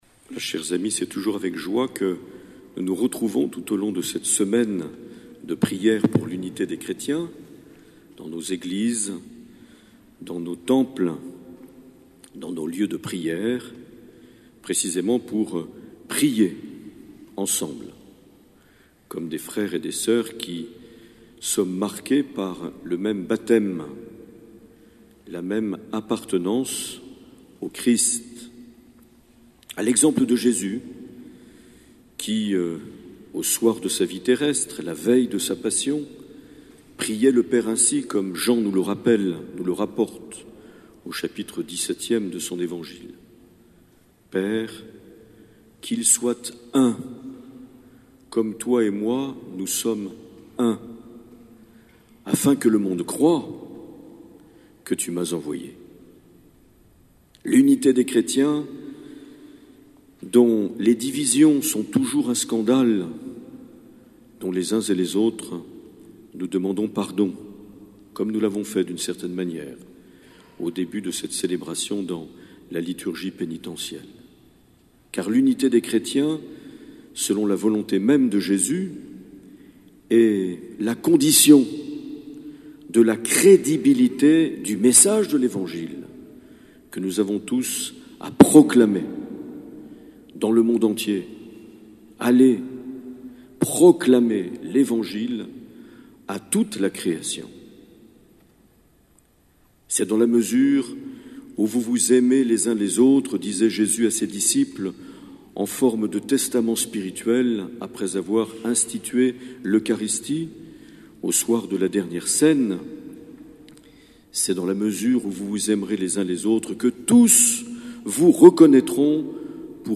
19 janvier 2016 - Sainte Eugénie de Biarritz - Célébration oecuménique
Les Homélies
Une émission présentée par Monseigneur Marc Aillet